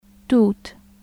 /tu/[tu] と/t̩u/[tʶʊ]
توت /tuːt/ [tuːt]　桑の木，桑の実